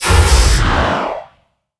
transport.wav